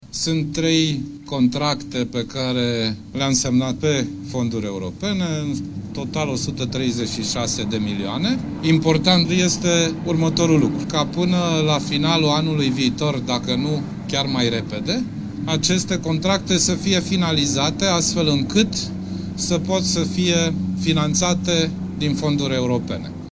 Ministrul Transporturilor, Sorin Grindeanu: